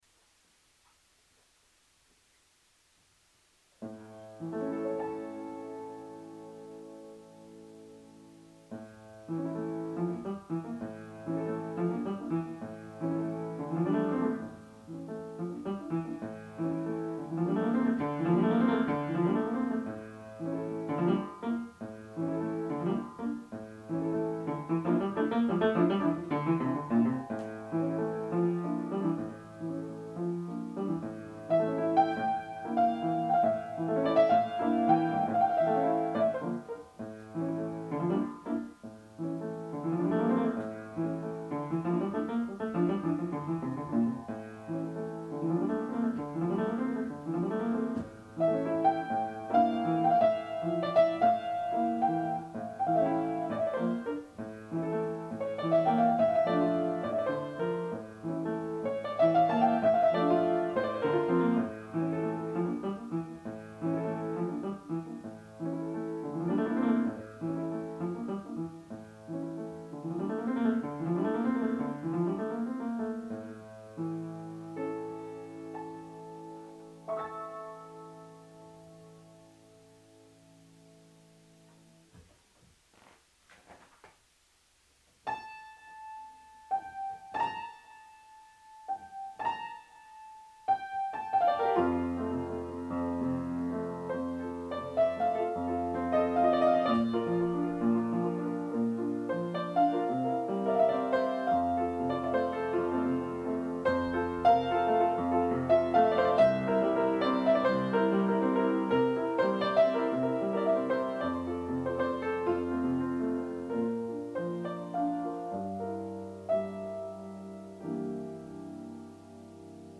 Divers Voiles de Claude Debussy ( Préludes , I) Rumores de la Caleta d'I. Albeniz . Le même mais au piano forte .